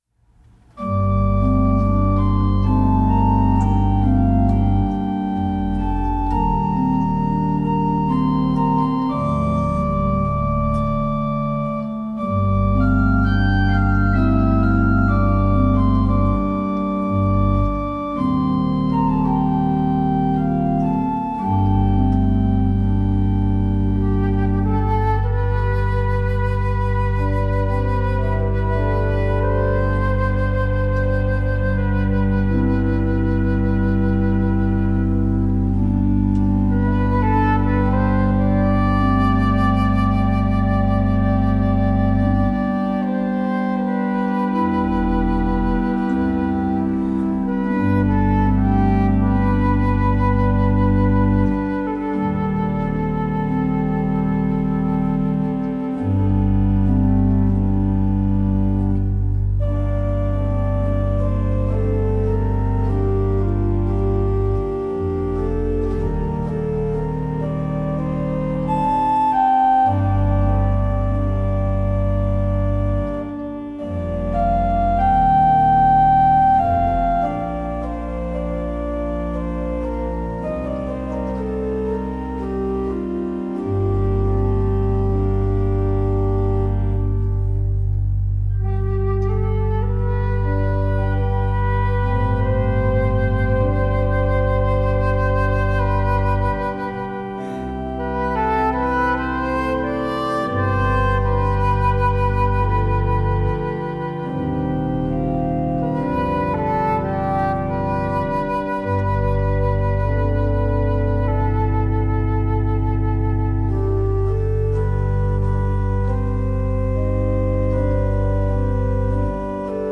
Musik begleitet mich Resonanz schaffen Musik begleitet mich (Klavier, Querflöte, Gesang/Chorleitung) von klein auf, natürlich auch immer beim Wandern und Pilgern.
Und dazu improvisieren wir meditativ